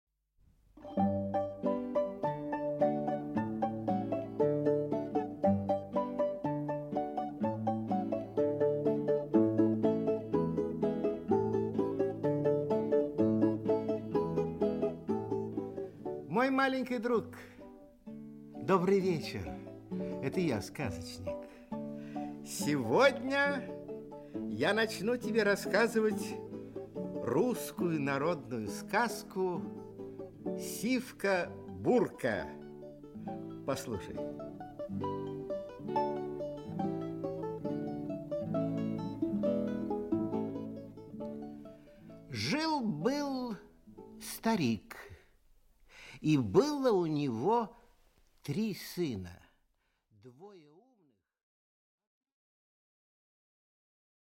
Аудиокнига Сивка-Бурка. Часть 1 | Библиотека аудиокниг
Часть 1 Автор Народное творчество Читает аудиокнигу Николай Литвинов.